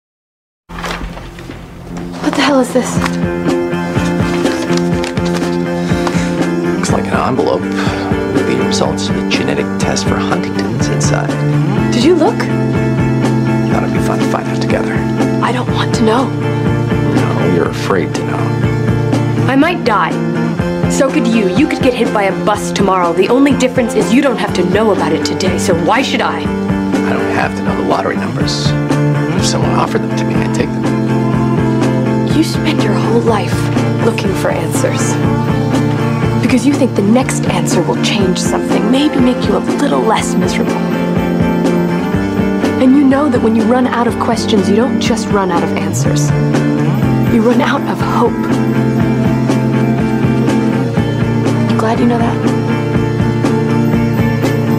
seriously this music makes anything sad